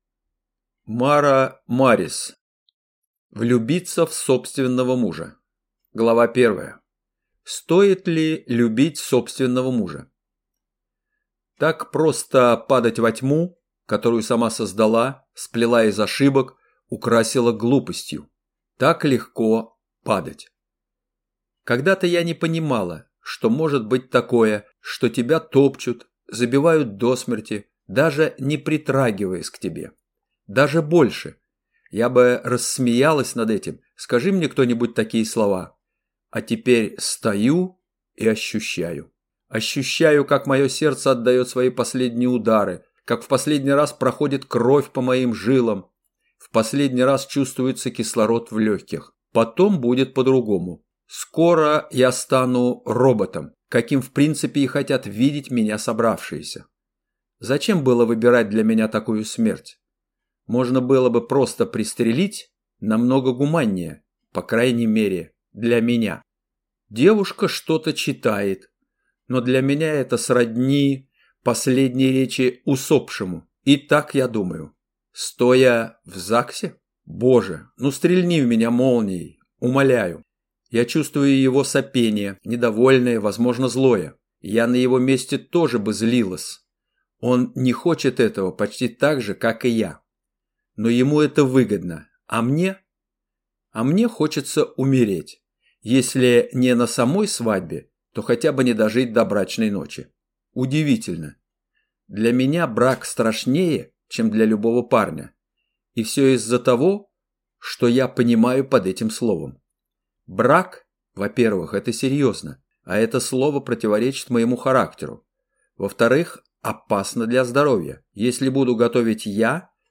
Аудиокнига Влюбиться в собственного мужа | Библиотека аудиокниг
Прослушать и бесплатно скачать фрагмент аудиокниги